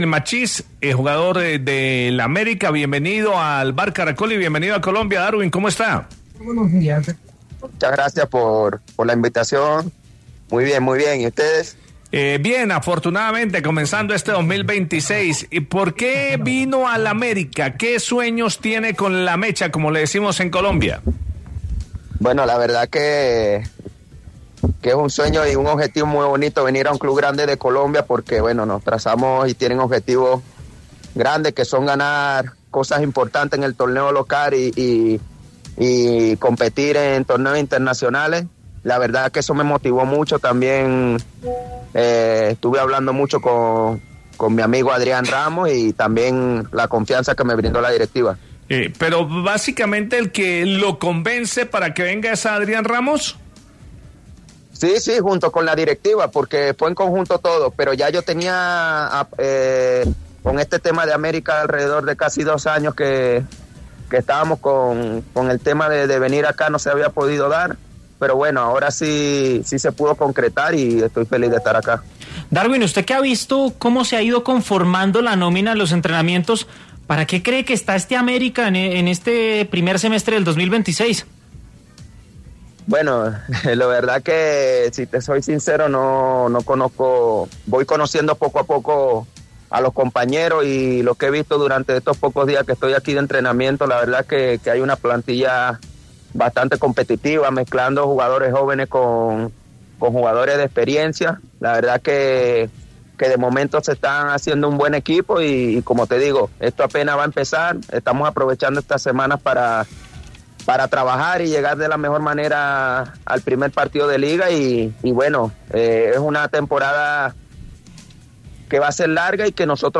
En conversación con El VBar Caracol, Machis explicó: “Es un sueño y un objetivo muy bonito venir a un club grande de Colombia, porque nos trazamos y tienen objetivos grandes que son ganar cosas importantes en el torneo local y competir en torneos internacionales. Estuve hablando mucho con mi amigo Adrián Ramos y también la confianza que me brindó la directiva”.